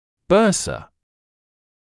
[‘bɜːsə][‘бёːсэ]синовиальная сумка; сумка, мешок (мн.ч. bursas, bursae [‘bɜːsɪ])